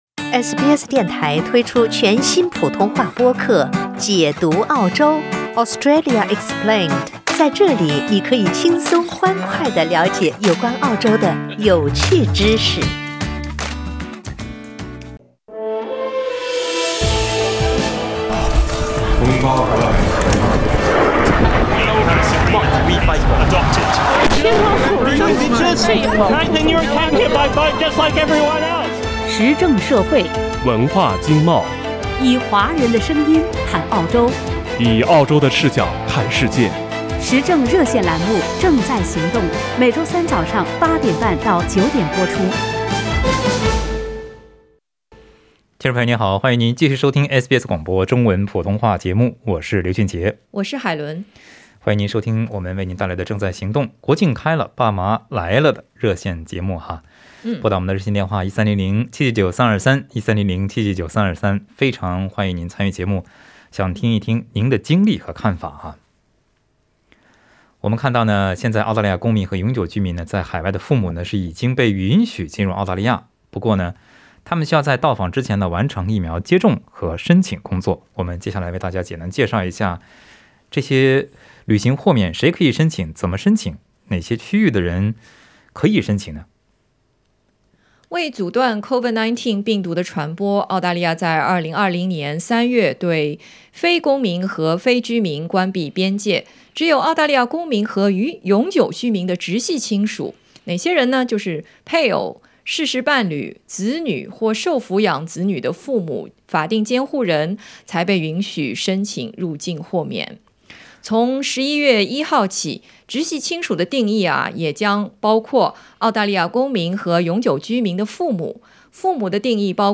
在本期《正在行动》热线节目中，听众朋友分享了自己的看法和经历。